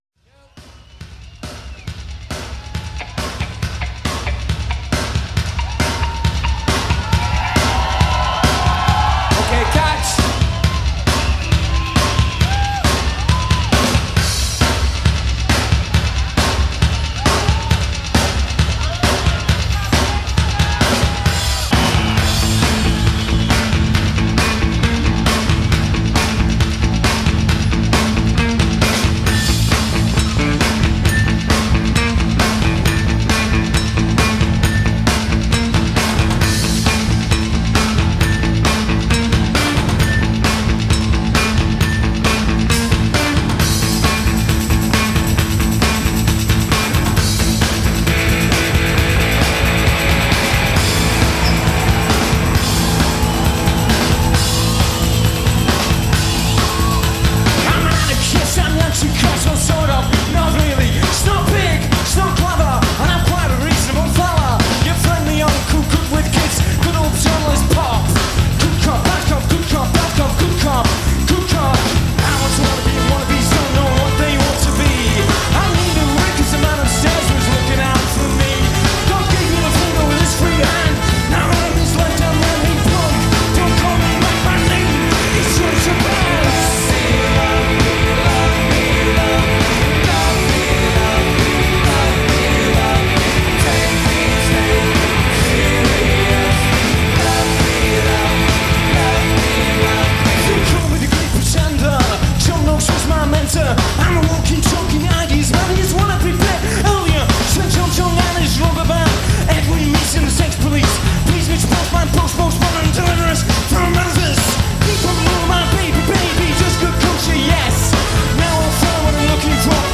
The band drew on genres such as punk rock, pop, and folk.